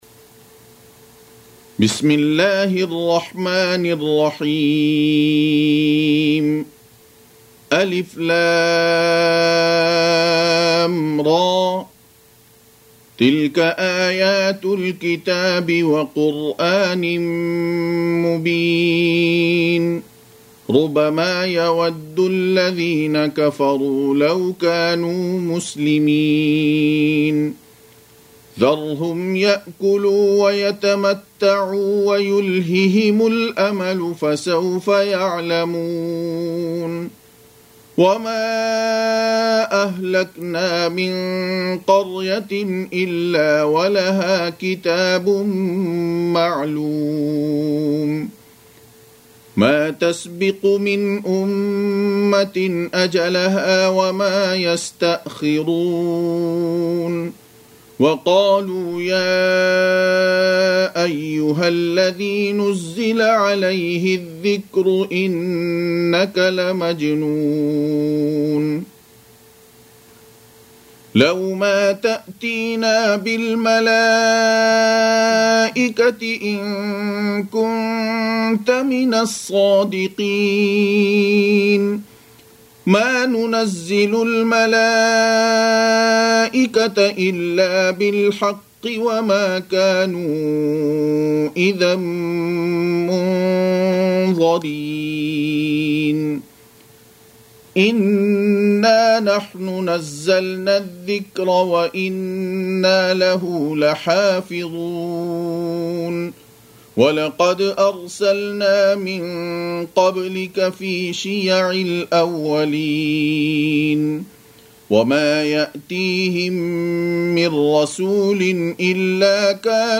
15. Surah Al-Hijr سورة الحجر Audio Quran Tarteel Recitation
Surah Repeating تكرار السورة Download Surah حمّل السورة Reciting Murattalah Audio for 15.